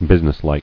[busi·ness·like]